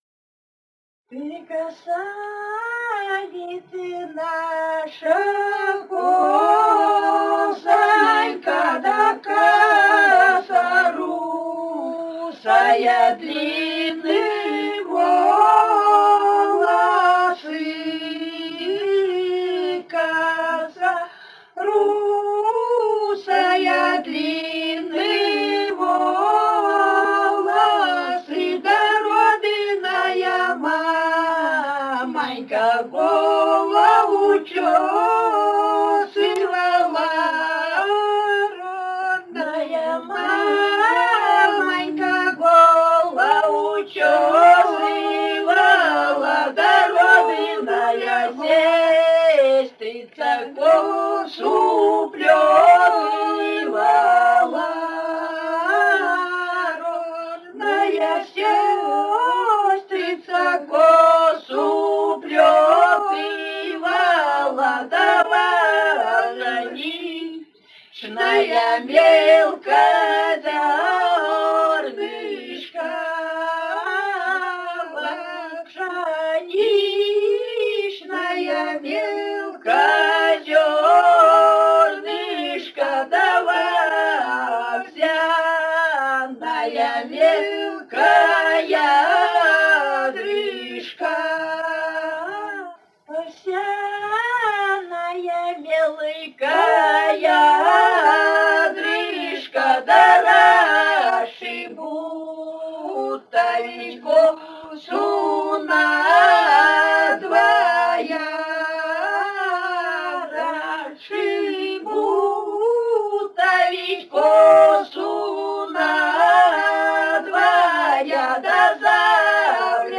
с. Урыль Катон-Карагайского р-на Восточно-Казахстанской обл.